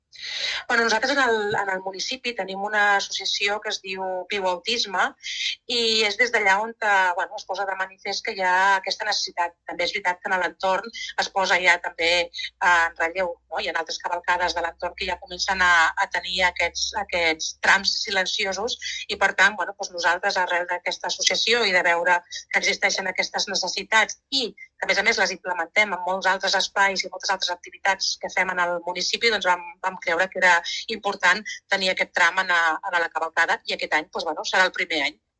La regidora de cultura del municipi, Maria Blanco, explica que la necessitat d’incorporar aquesta zona al recorregut de la cavalcada va néixer des del mateix ajuntament, veient que certa part de la població quedava exclosa d’aquests tipus d’activitats.